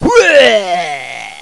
macpuke.mp3